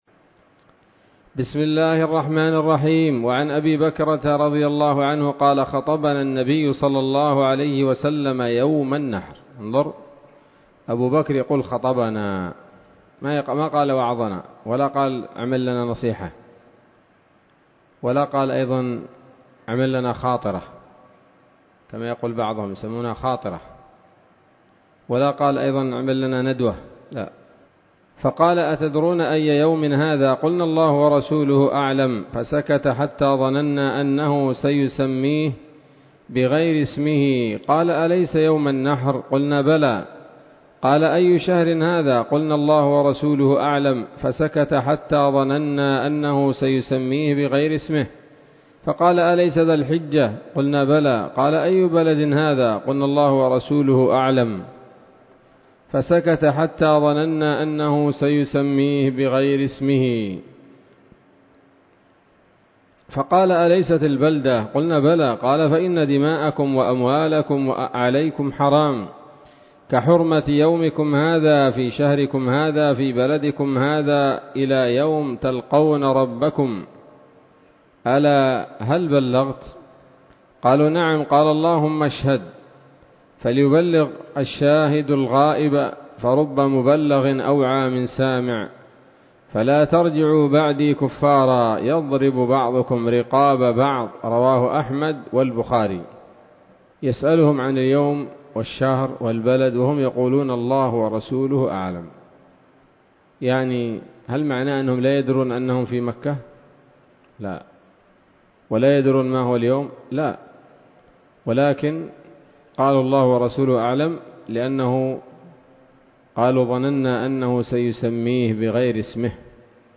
الدرس السادس عشر من ‌‌‌‌كتاب العيدين من نيل الأوطار